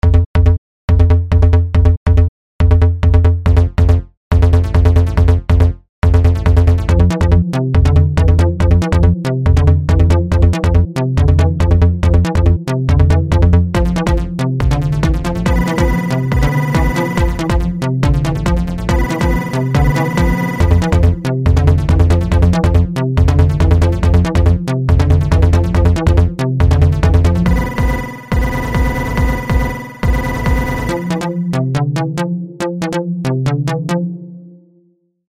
Instrumental
Minimal!